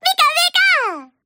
pikachu-starter.ogg